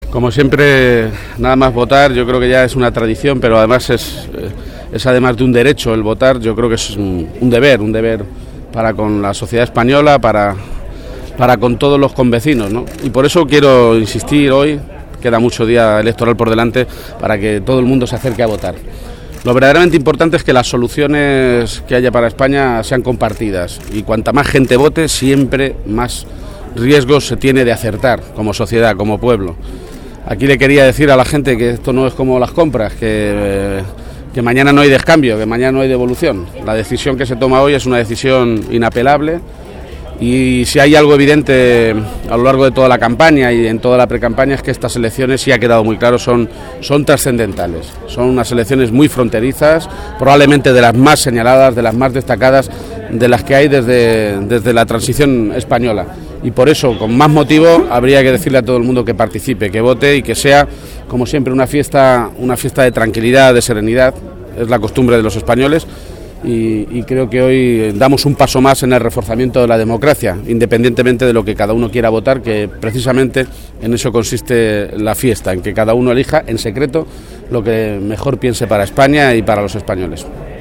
García-Page, que ha realizado estas manifestaciones tras ejercer su derecho al voto en el colegio público “Ciudad de Nara” de la capital regional, ha afirmado que la decisión que se tome hoy es muy importante.
Cortes de audio de la rueda de prensa